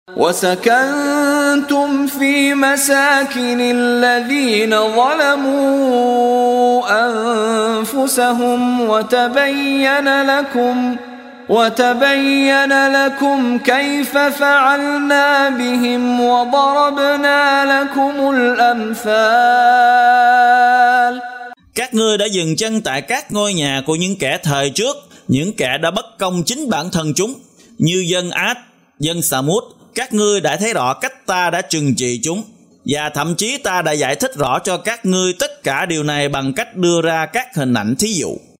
Đọc ý nghĩa nội dung chương Ibrahim bằng tiếng Việt có đính kèm giọng xướng đọc Qur’an